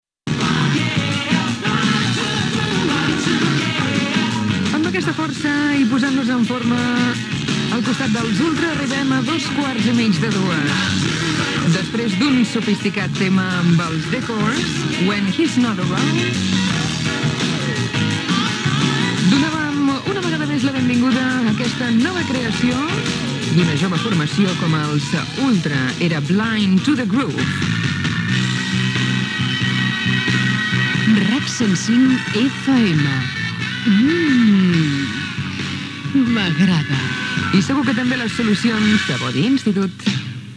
cf086129e71815c3be26c1eee13d67b197b1d898.mp3 Títol RAC 105 Emissora RAC 105 Cadena RAC Titularitat Privada nacional Descripció Presentació d'un disc i indicatiu de l'emissora.